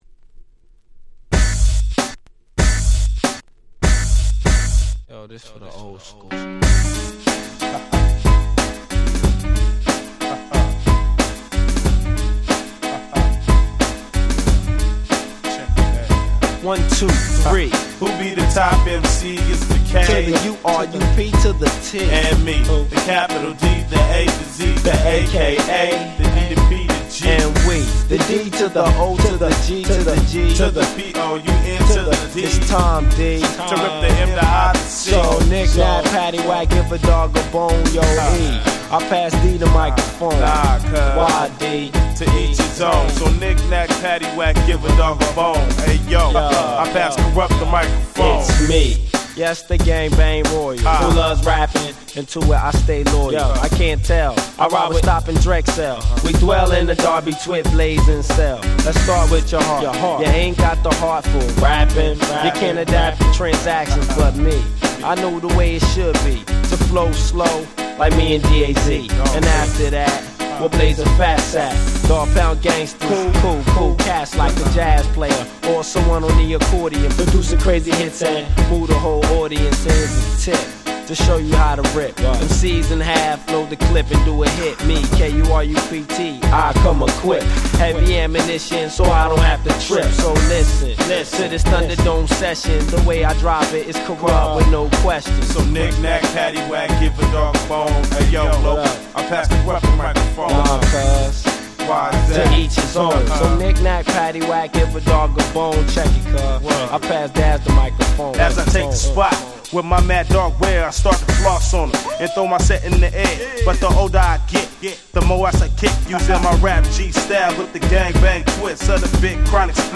98' Nice West Coast Hip Hop !!